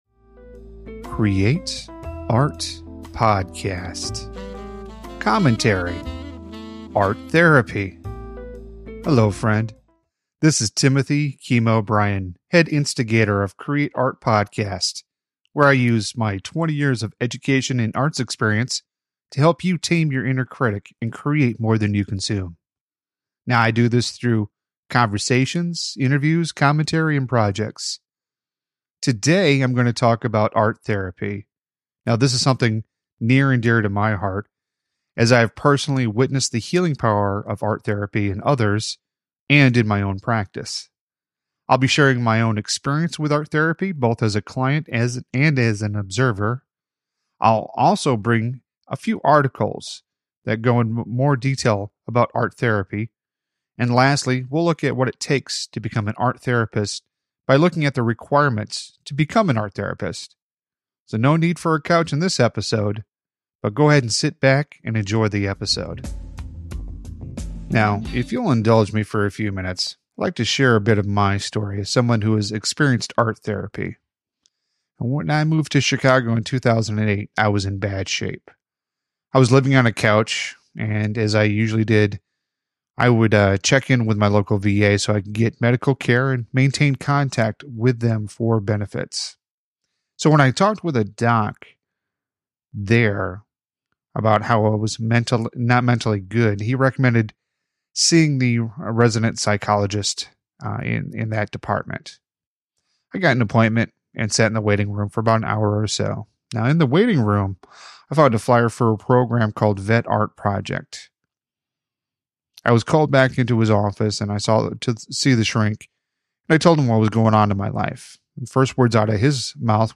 Commentary : Art Therapy